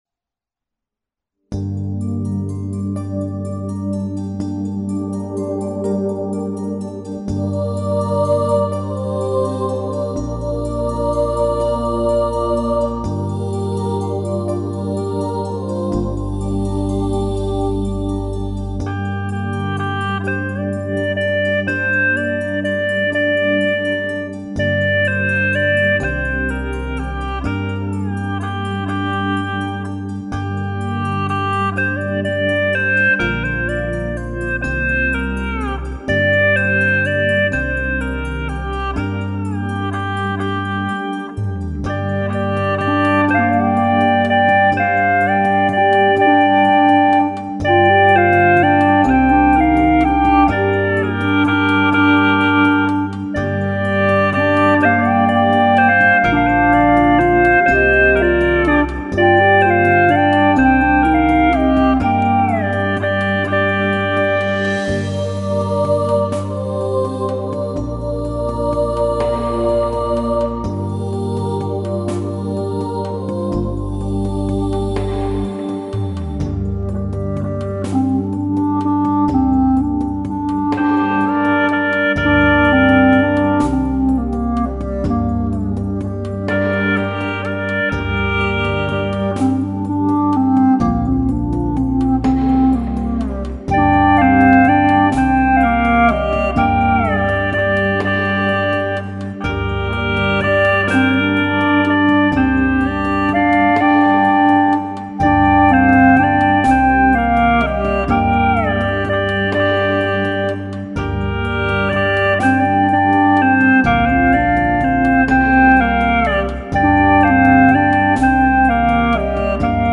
《木叶情歌》三重奏
调式 : 降B